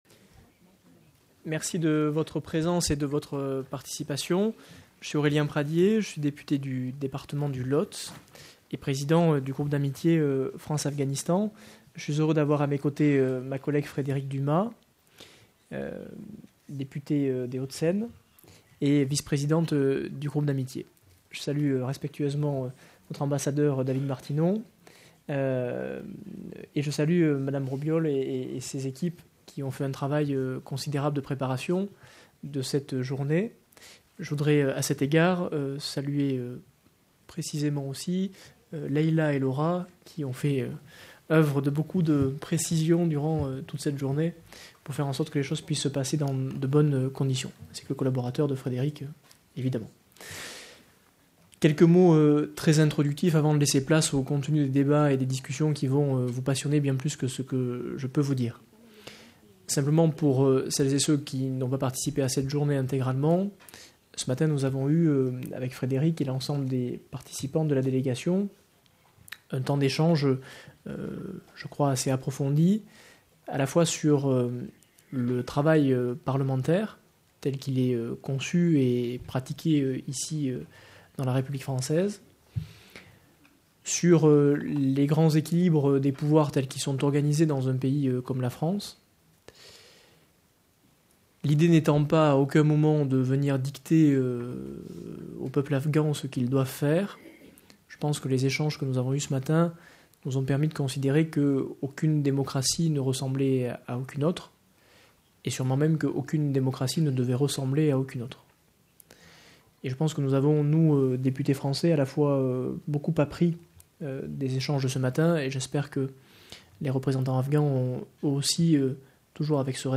A conference took place at the French Parliament* on Wednesday June 19th, 2019 at 6 pm (France time).